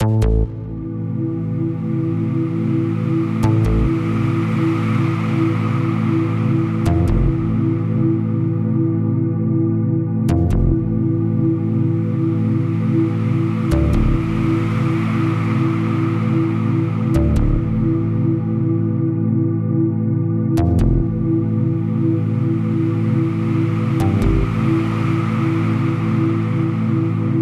Tag: 70 bpm Ambient Loops Pad Loops 4.62 MB wav Key : A